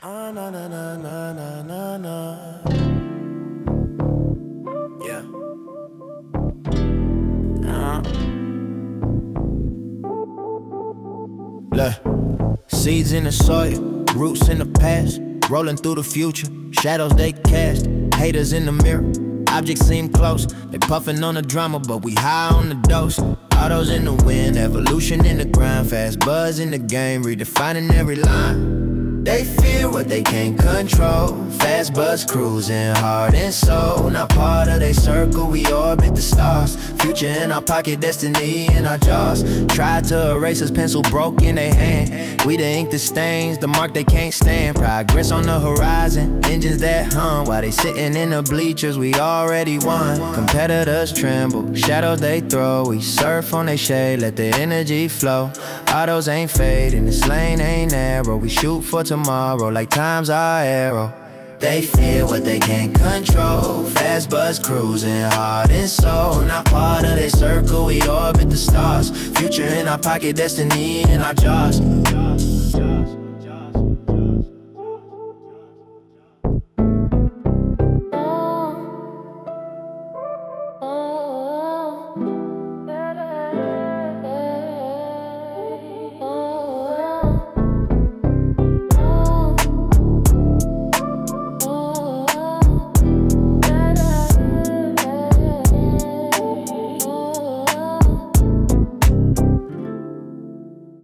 soul 17 Dec 2025